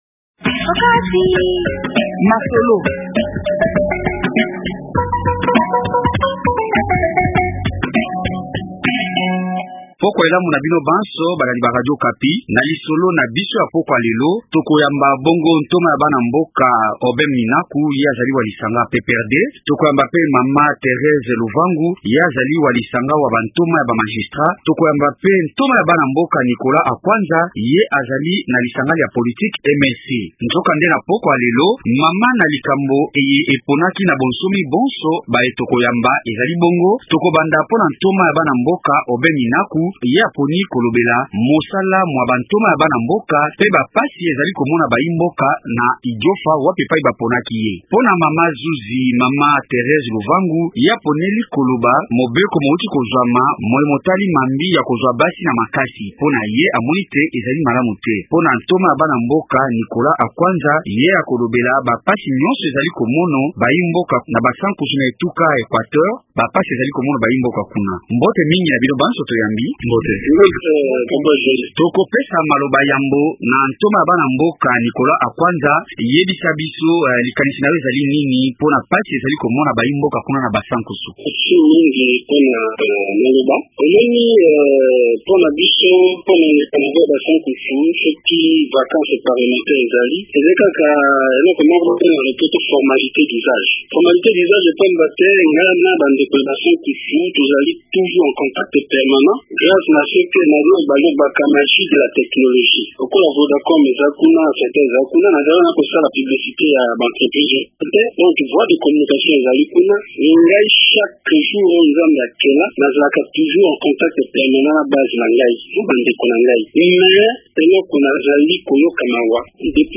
Bamonisi ba opposition, majorité au pouvoir to baye bazali kokamba ekolo mikolo miye mpe société civile bazali kopesa makasi ma bango na likambo liye . rnInvités :